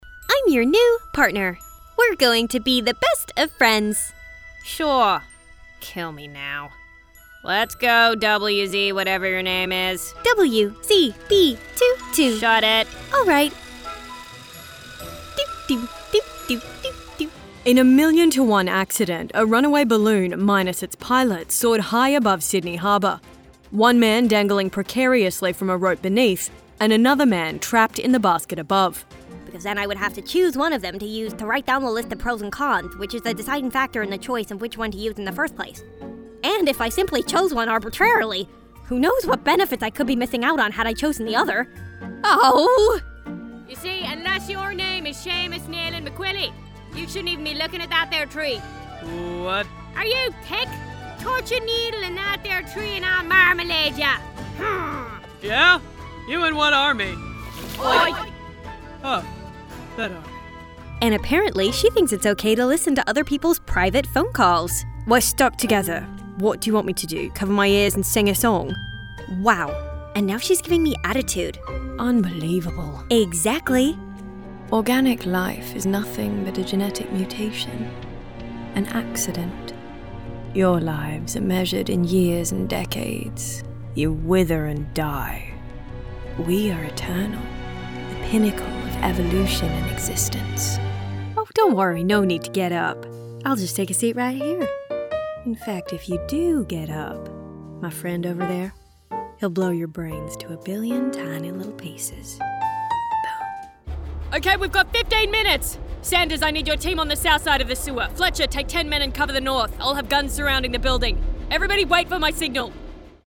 Animation and Gaming Demo
Vocal qualities: Conversational, believable, friendly, warm, upbeat, cool, natural, engaging, relatable, confident, professional.